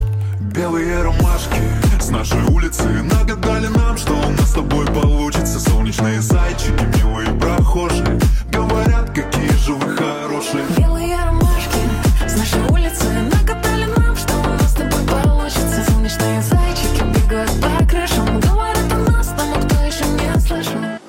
поп
басы , битовые